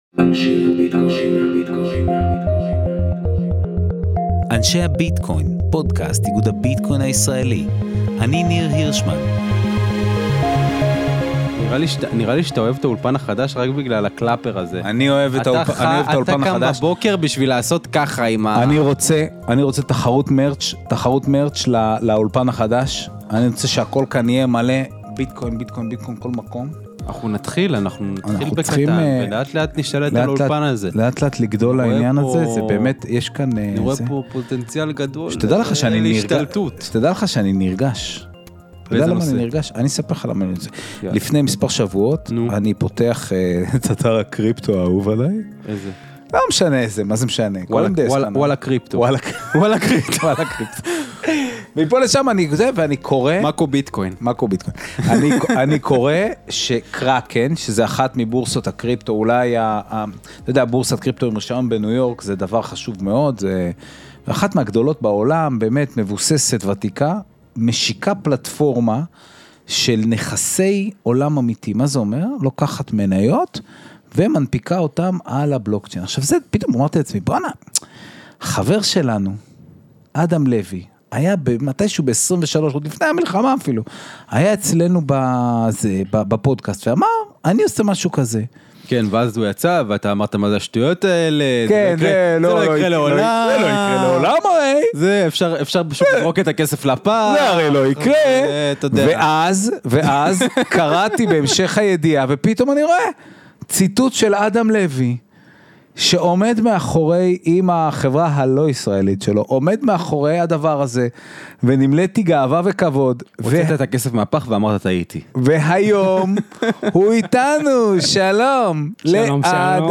שיחה